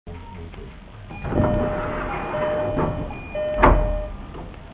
なお、足元の点字ブロックやホームとドアの段差などの解決はE531系に準じたものであるが、ドアモーターとドアチャイムの音量は山手線E231系と同じである。
ドア開|
dooropen.mp3